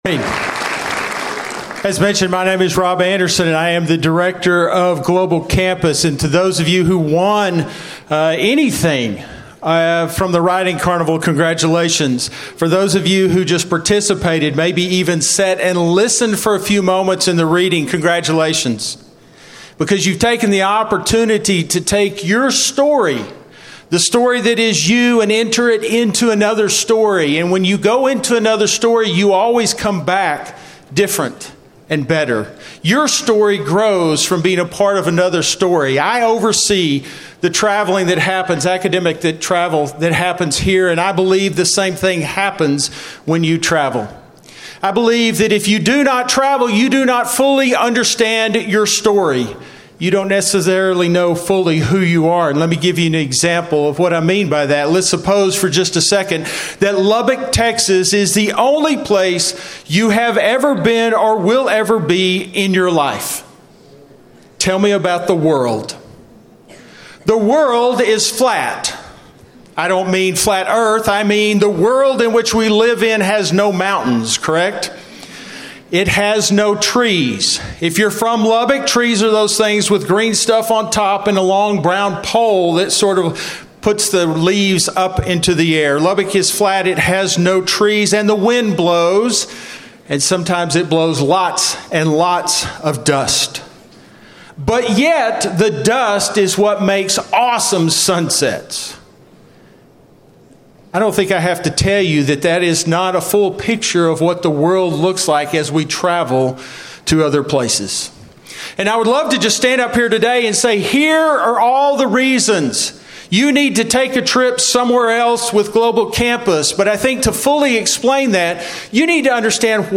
LCU Chapel Podcast - Why We Travel: Stories That Change Us